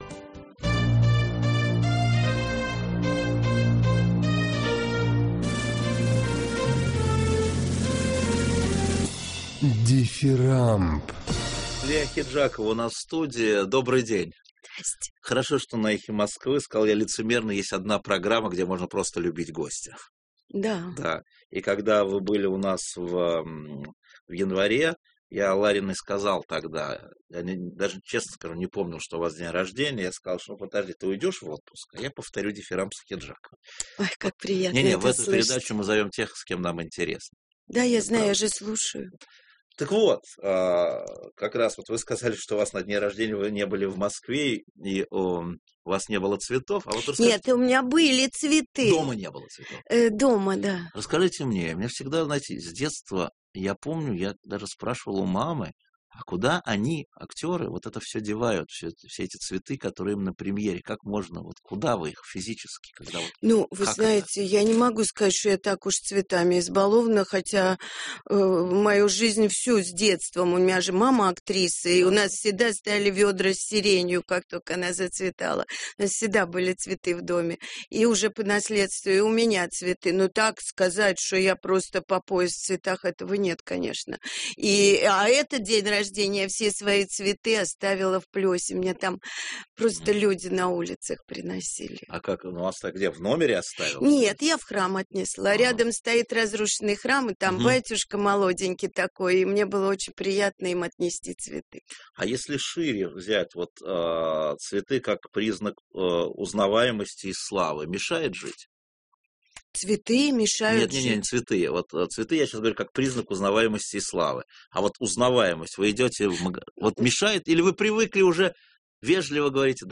А.ВЕНЕДИКТОВ: Лия Ахеджакова у нас в студии. Добрый день.